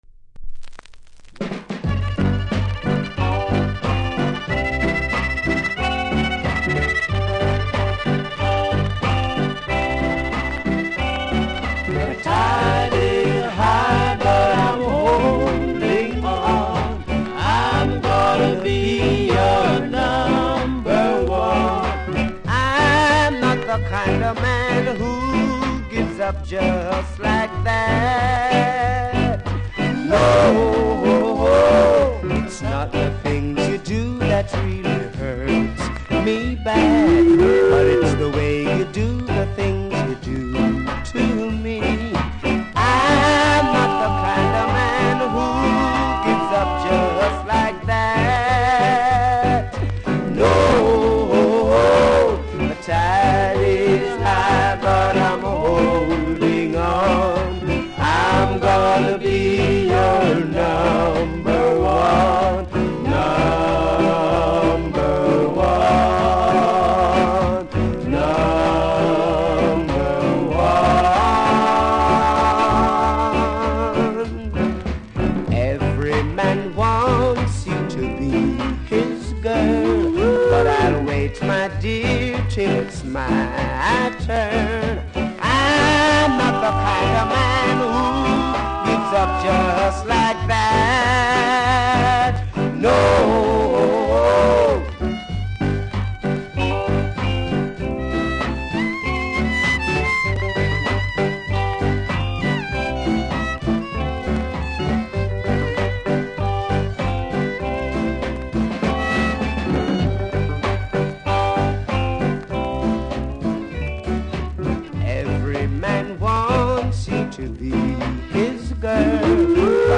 オリジナル盤は全部録音レベルが高くて声割れしていますね！
両面フルで録音しましたので試聴で確認下さい。